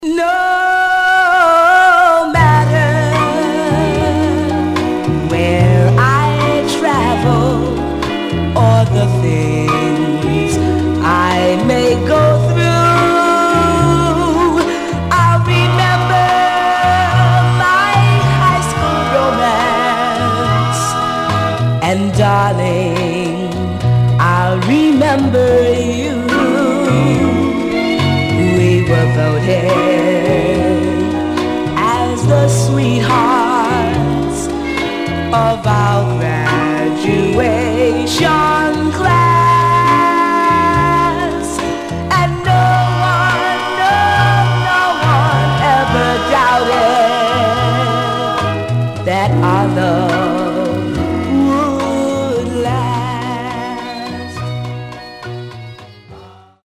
Mono
Male Black Group